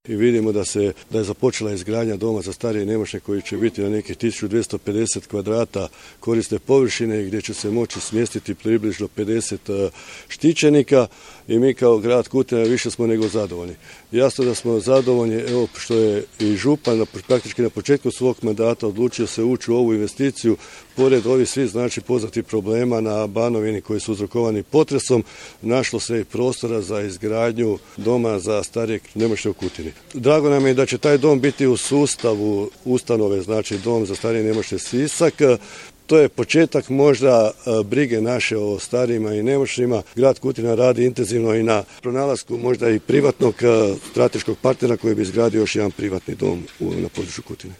Već duže vrijeme težnja građana Kutine je Dom za starije i nemoćne osobne, navodi gradonačelnik Zlatko Babić komentirajući radove